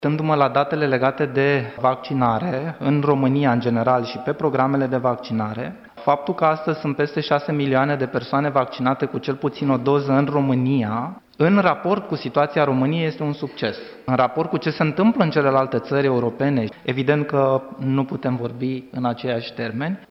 Forumul Pro Vaccinare în România își dorește să crească încrederea românilor în procesul de vaccinare. Medicul Valeriu Gheorghiță, președintele Comitetului Campaniei de vaccinare din România spune că nu se poate vorbi de un eșec al campaniei de vaccinare: